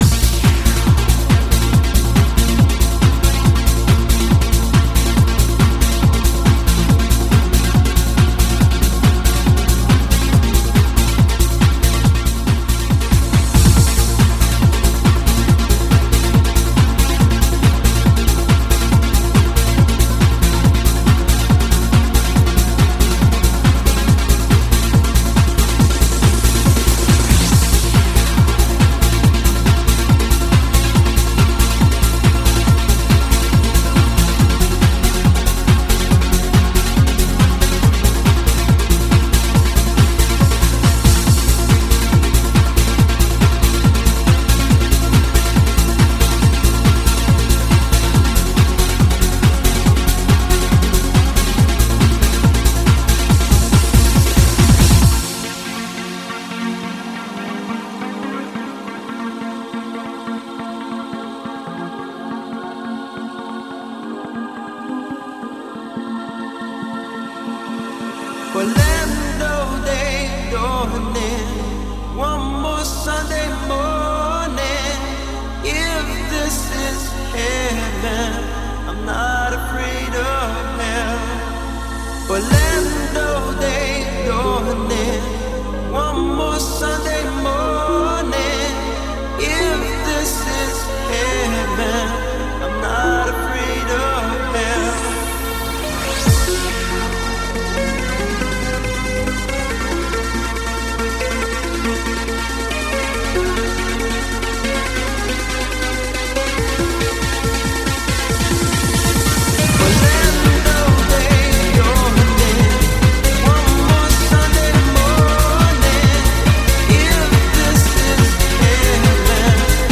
kőkemény hangzású de kellemes énekbetéttel dúsított klubdal